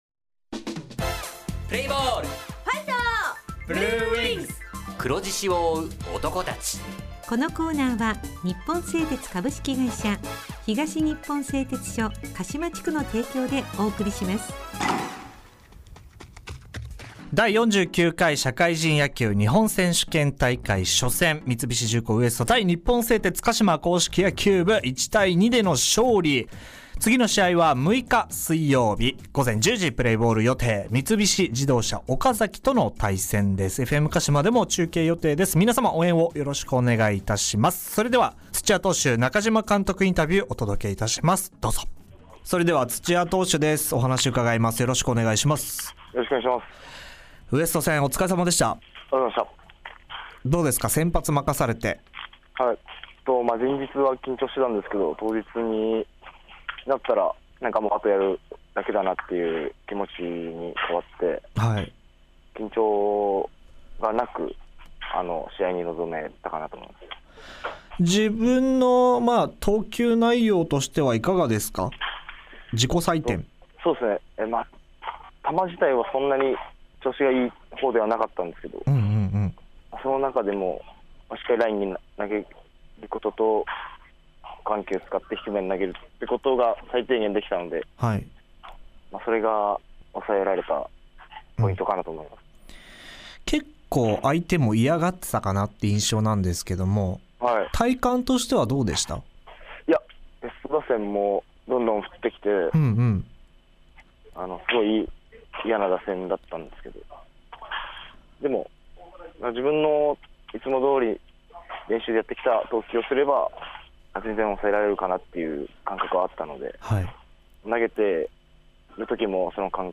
地元ＦＭ放送局「エフエムかしま」にて鹿島硬式野球部の番組放送しています。